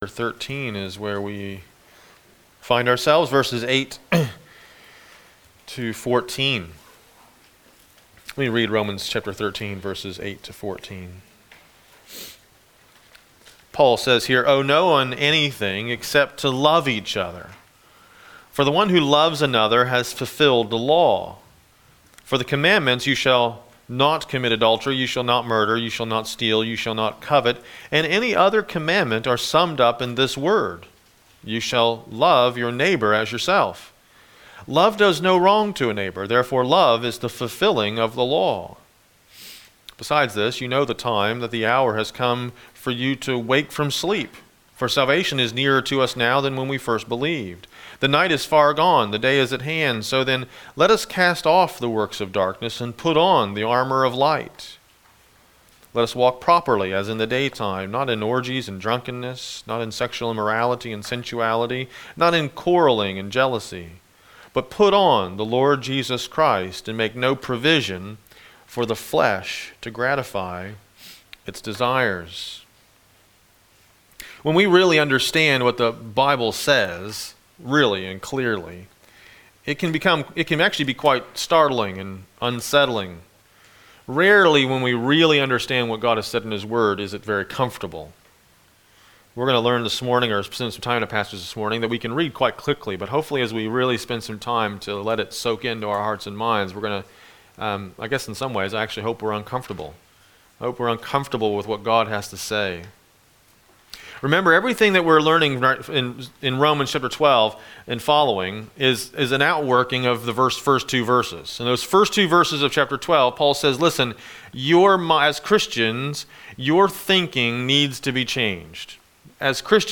This is a video of our full service.